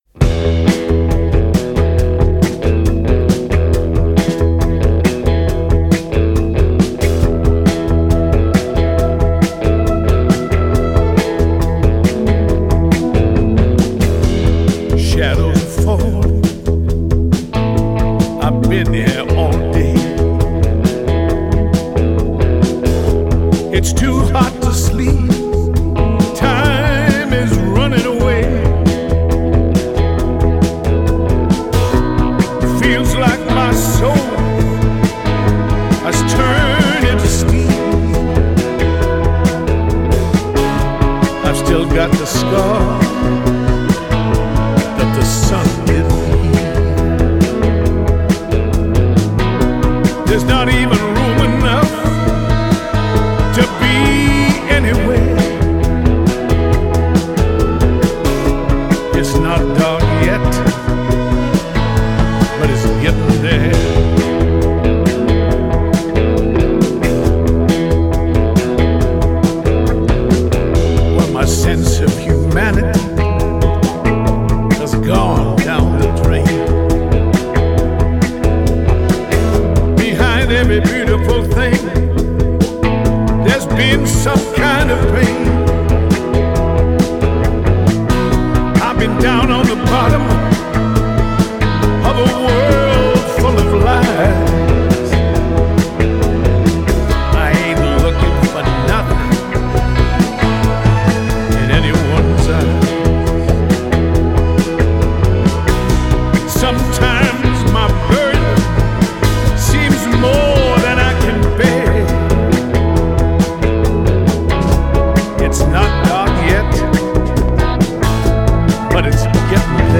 получился бодрый рок